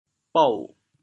“傅”字用潮州话怎么说？
bou3.mp3